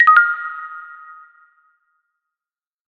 tweet_received.ogg